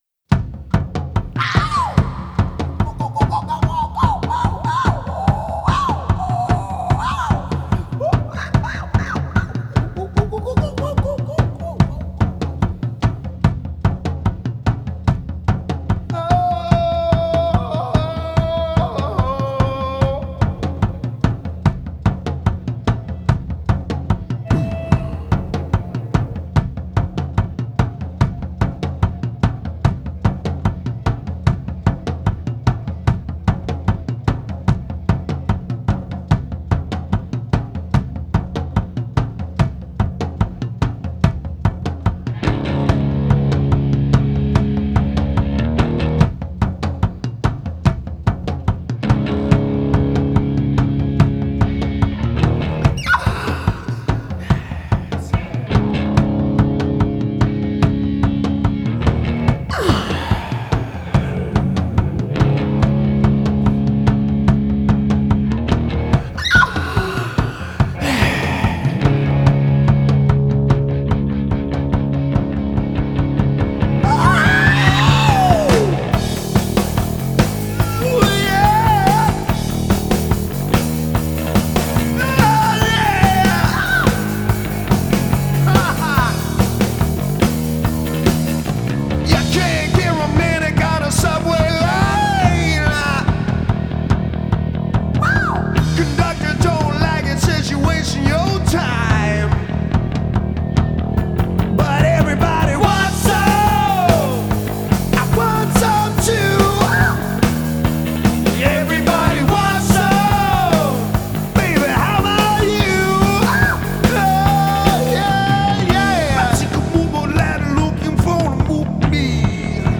These are my mixes!!!
No Guitar